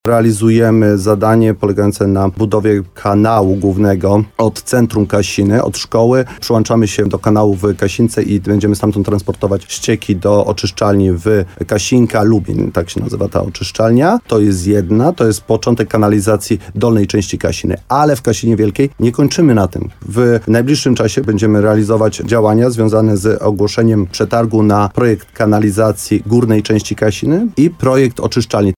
– Musimy to robić, bo nie wyobrażam sobie, że ścieki nadal będą płynąć rzekami – powiedział w programie Słowo za Słowo w radiu RDN Nowy Sącz wójt gminy Mszana Dolna Mirosław Cichorz.
Rozmowa z Mirosławem Cichorzem: Tagi: HOT Kasina Wielka gmina Mszana Dolna Mirosław Cichorz Nowy Sącz Słowo za Słowo budowa kanalizacja